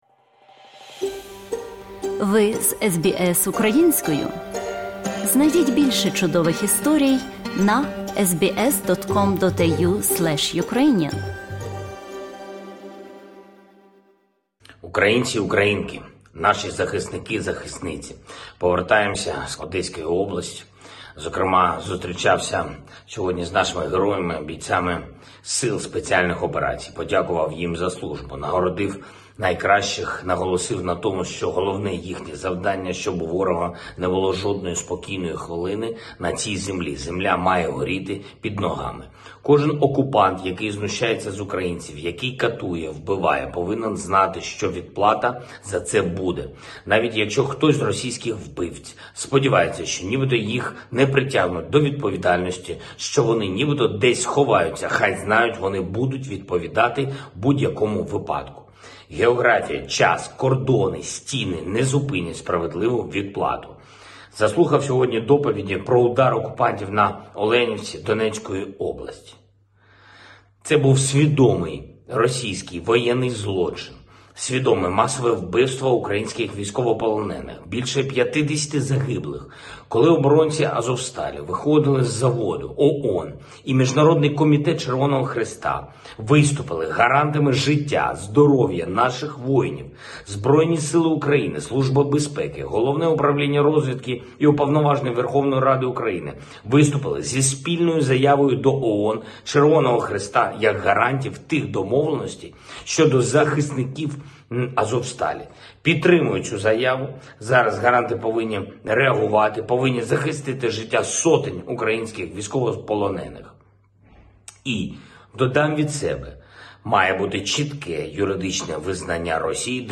Address by President Volodymyr Zelenskyy to Ukrainians